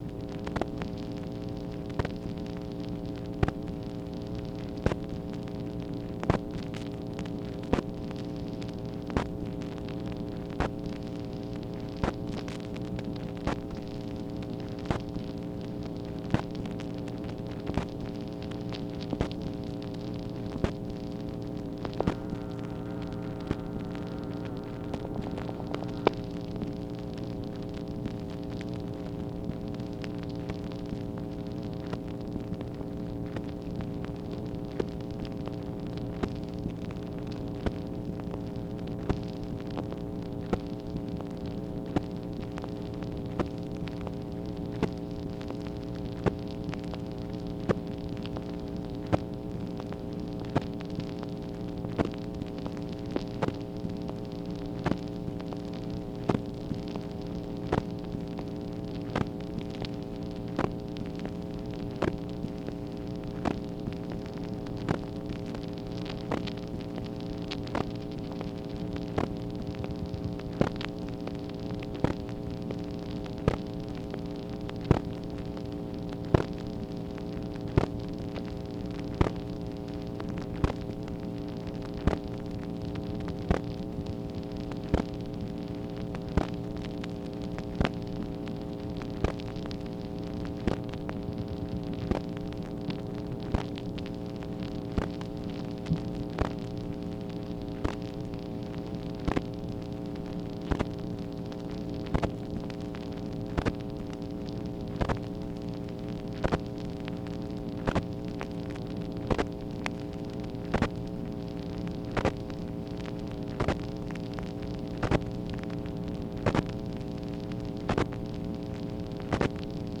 MACHINE NOISE, August 17, 1964
Secret White House Tapes | Lyndon B. Johnson Presidency